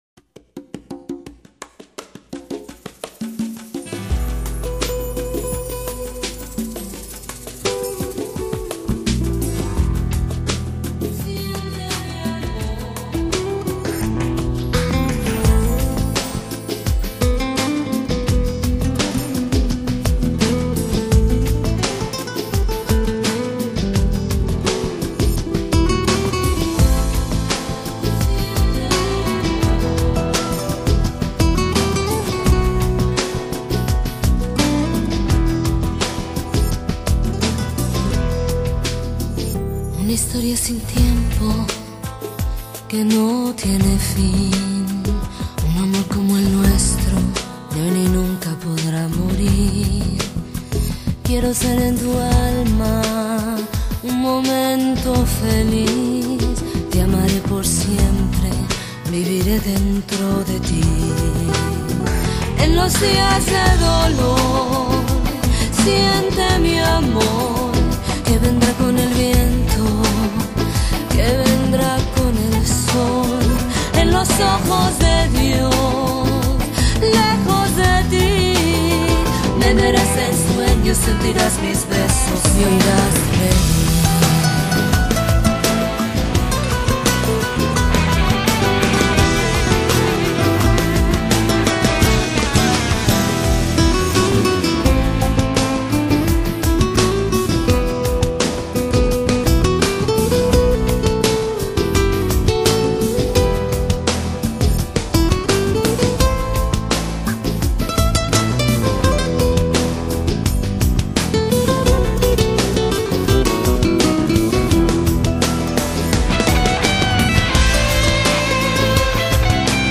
拉丁的情调让人微醺
清脆的吉他弦音、飘渺的女声